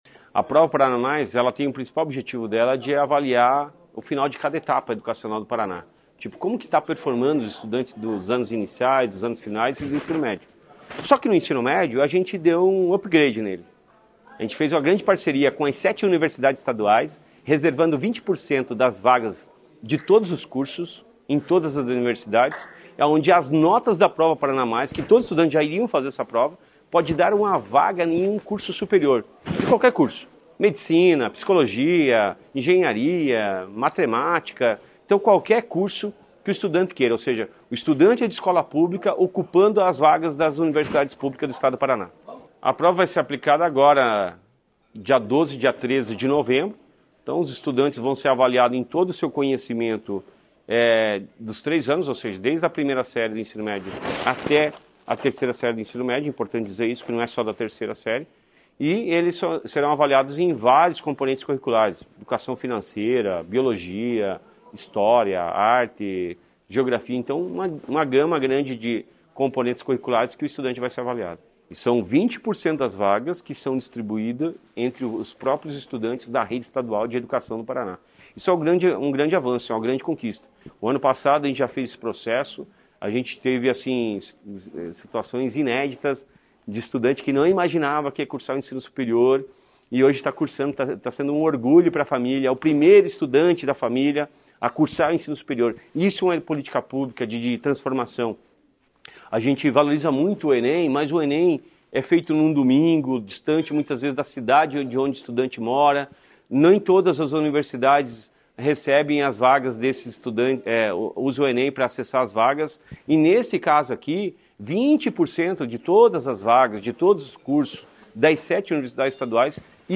Sonora do secretário da Educação, Roni Miranda, sobre a Prova Paraná Mais